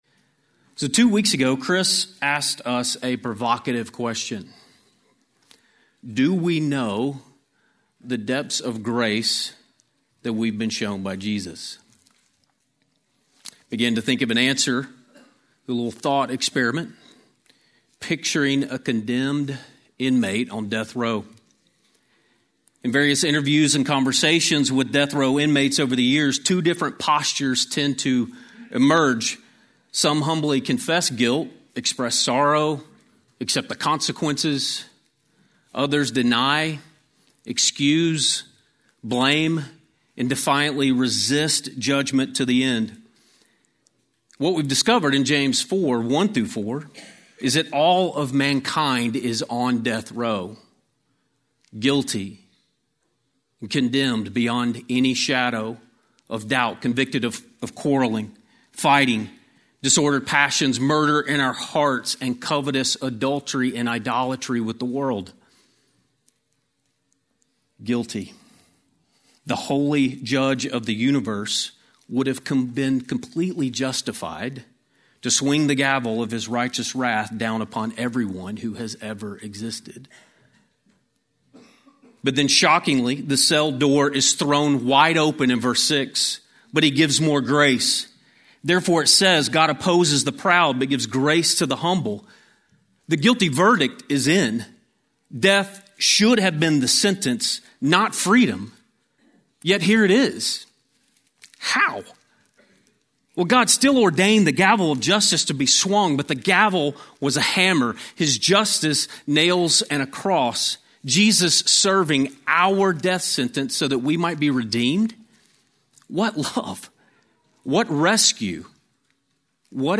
A sermon on James 4:7-10